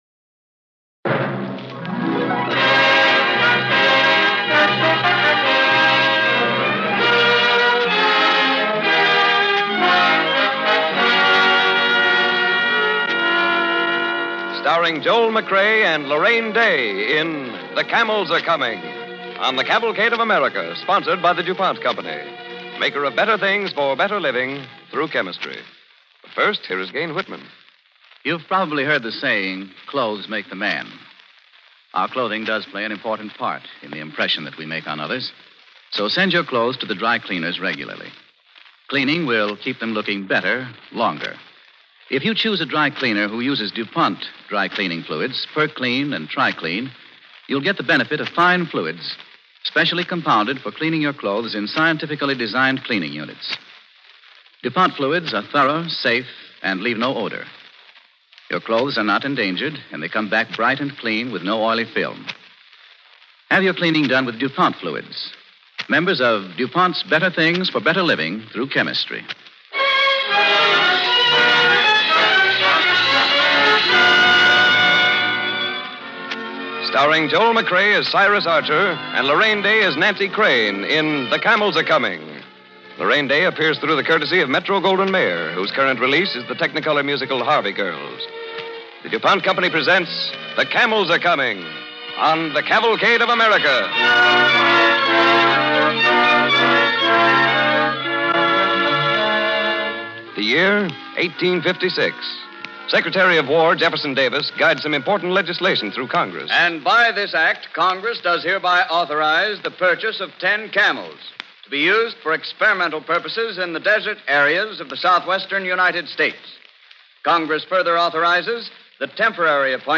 The Camels are Coming, starring Joel McCrea and Larraine Day